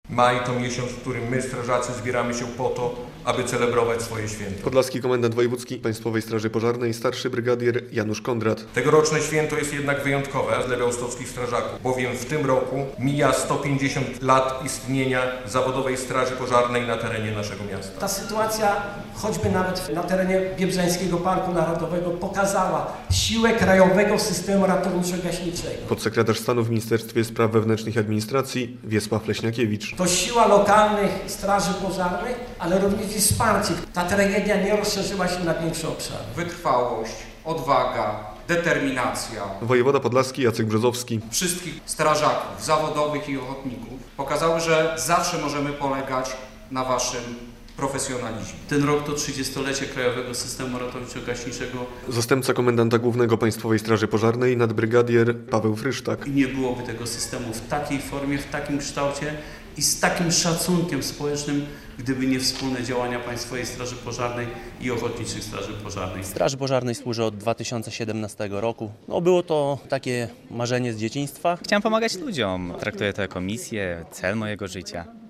Okazją były obchody jubileuszu 150-lecia Zawodowej Straży Pożarnej w Białymstoku, połączone z Wojewódzkimi Obchodami Dnia Strażaka.
Dzisiaj oddajemy honor i cześć tym wszystkim, którzy 150 lat temu myśleli o budowaniu bezpieczeństwa (...), już wtedy mimo, że był to zabór rosyjski, ale przecież większość tych strażaków to byli Polacy służyli mieszkańcom tego miasta, a wszyscy pozostali służyli mieszkańcom tej ziemi - powiedział w przemówieniu wiceminister Leśniakiewicz.